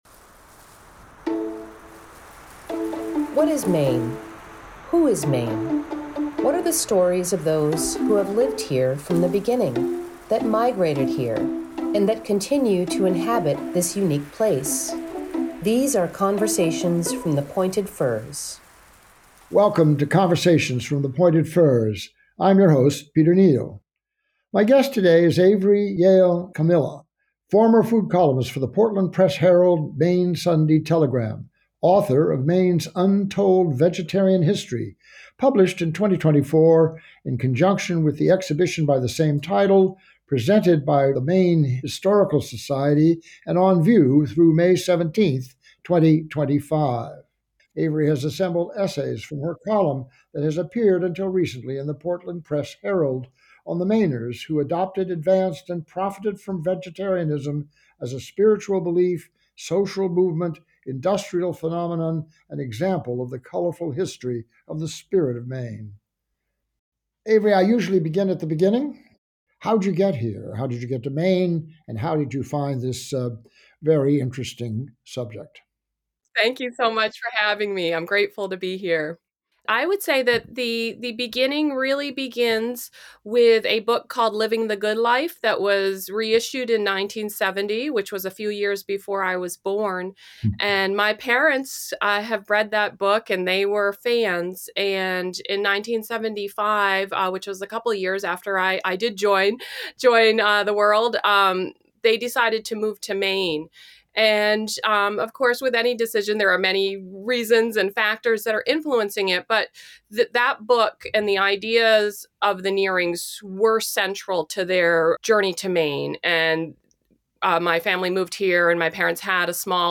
Conversations from the Pointed Firs is a monthly audio series with Maine-connected authors and artists discussing new books and creative projects that invoke the spirit of Maine, its history, its ecology, its culture, and its contribution to community and quality of life.